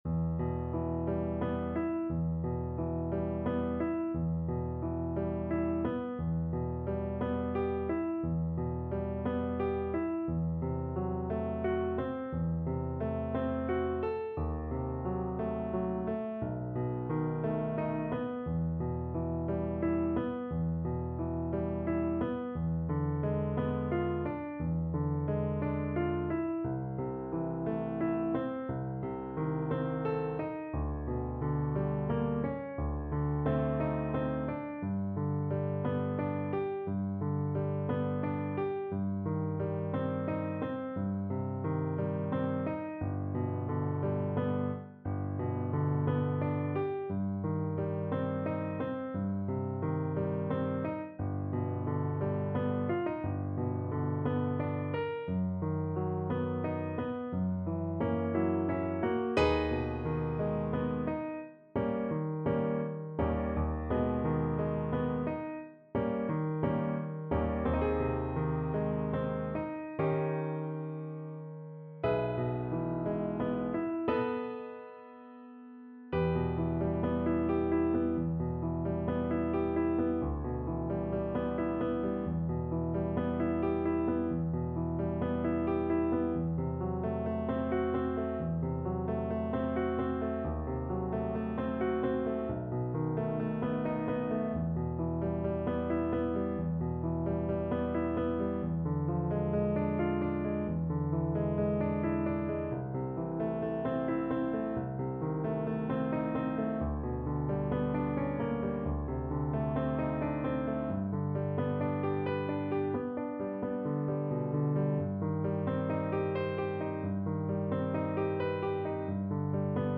Play (or use space bar on your keyboard) Pause Music Playalong - Piano Accompaniment Playalong Band Accompaniment not yet available transpose reset tempo print settings full screen
E minor (Sounding Pitch) (View more E minor Music for Violin )
~ = 88 Malinconico espressivo
3/4 (View more 3/4 Music)